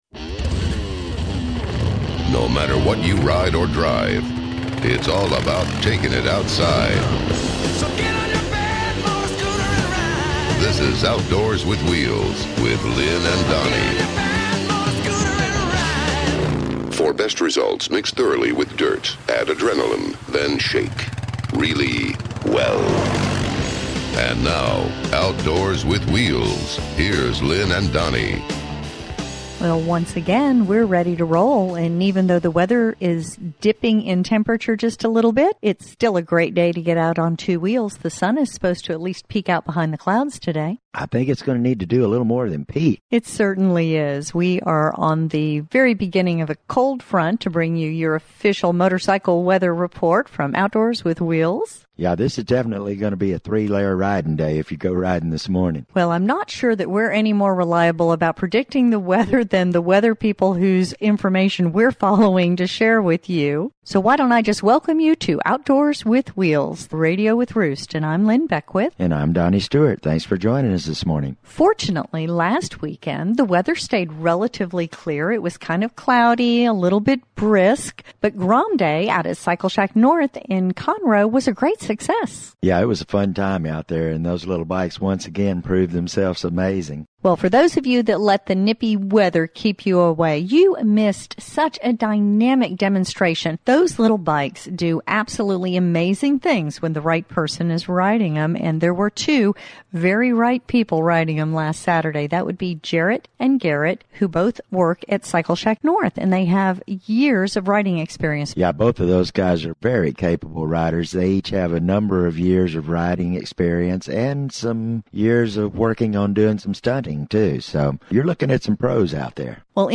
Okay, why is everyone gaga over these lil’ 125cc wonders? Join us this week at Cycle Shack North’s Grom Day for a peek inside the wacky world of wheelies, stoppies, stunts and all around fun that defines these lean machines.